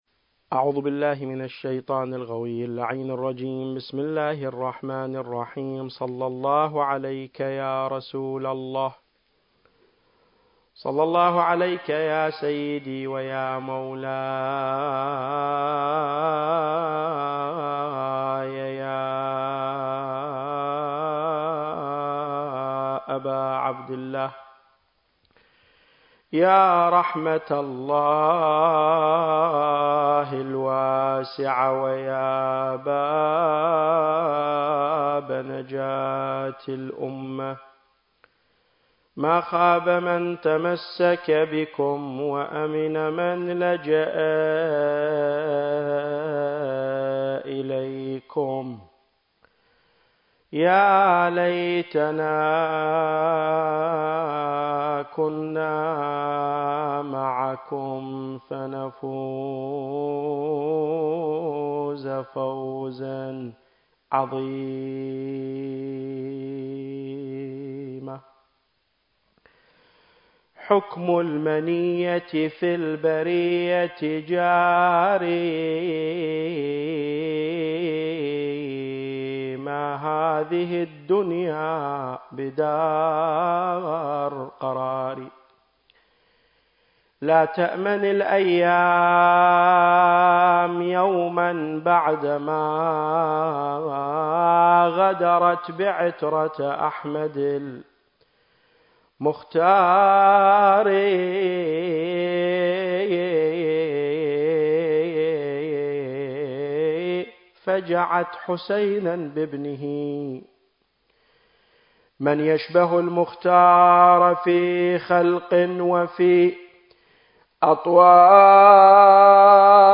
محاضرات في صدى النهضة الحسينية المكان: المركز العلمي الحوزوي - معهد تراث الأنبياء - النجف الأشرف التاريخ: محرم الحرام 1442 للهجرة